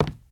PixelPerfectionCE/assets/minecraft/sounds/step/wood6.ogg at bda80e1fbaa41816d607c08c1af4a23bef01b2af
wood6.ogg